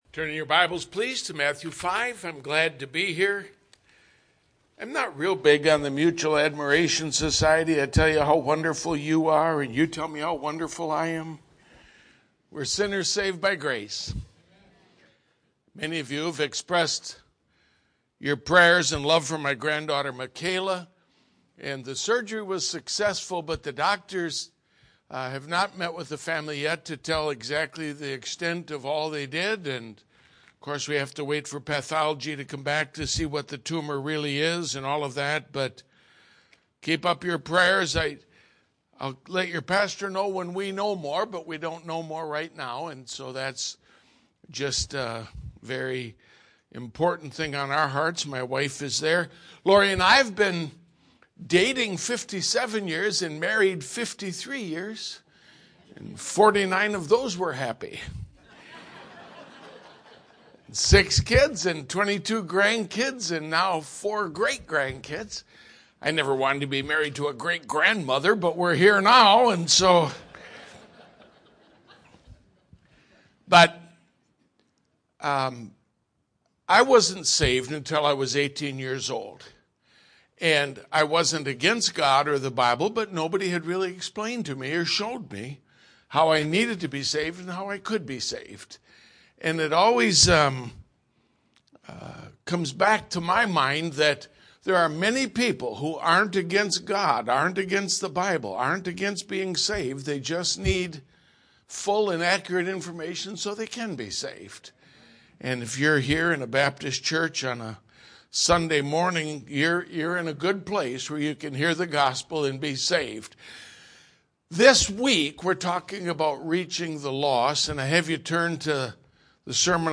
Bible Believers Baptist Church